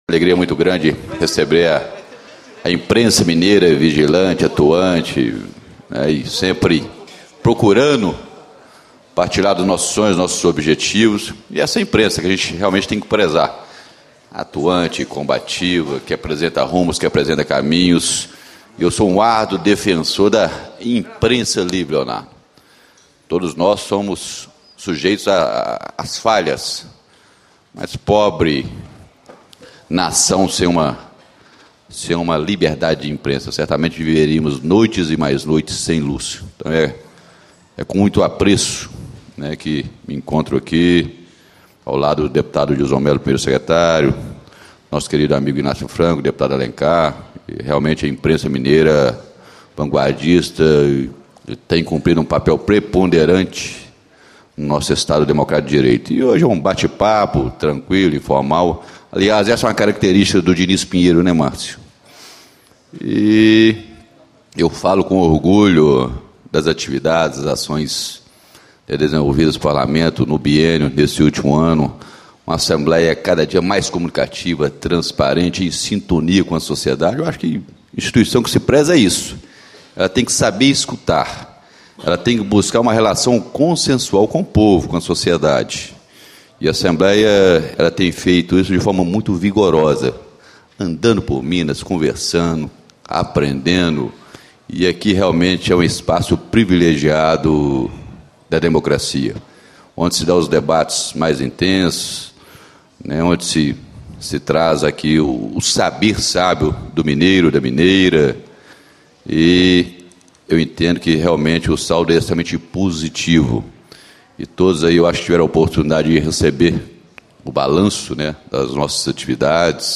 Deputado Dinis Pinheiro, Presidente da Assembleia Legislativa de Minas Gerais. Balanço final para a imprensa.